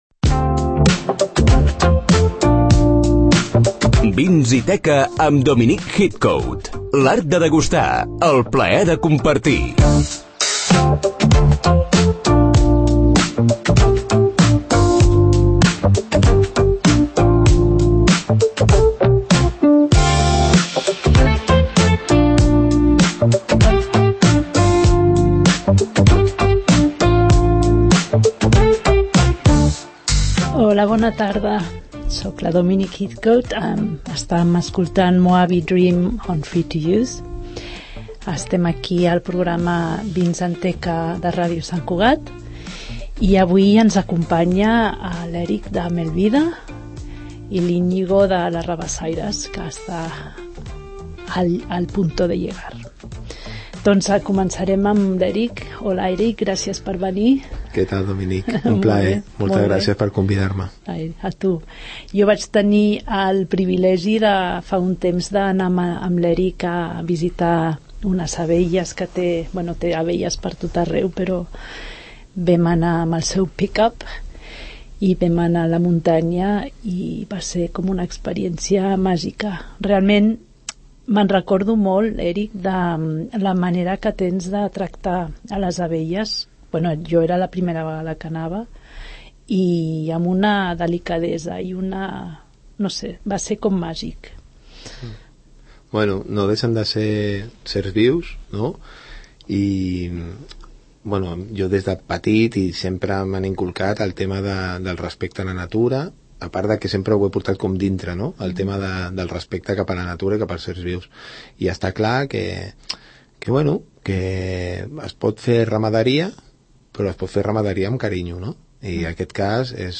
El p�dcast especialitzat en vins i gastronomia 'Vins & teca' estrena un nou cap�tol, amb convidats del sector vitivin�cola i de la restauraci�.
Tot plegat, acompanyat de bona m�sica i moltes ganes de compartir, aprendre i degustar.